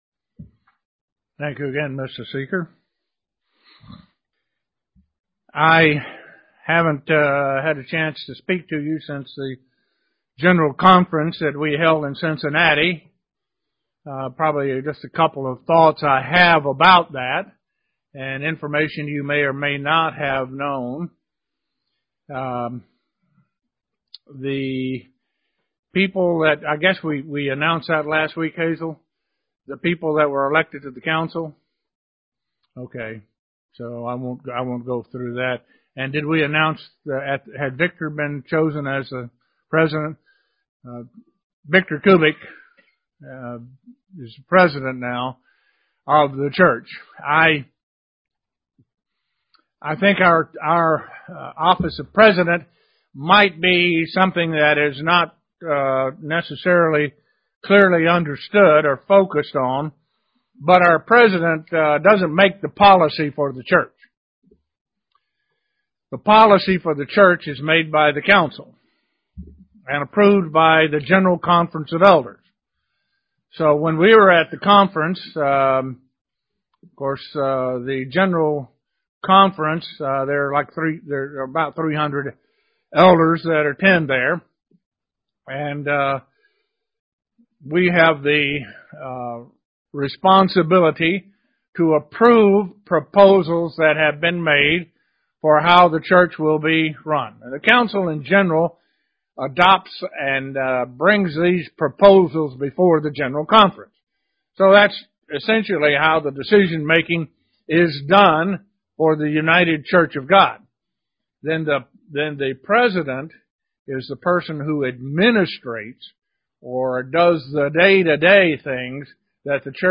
Given in Elmira, NY
Print Explanation of the seasons as Holy Days UCG Sermon Studying the bible?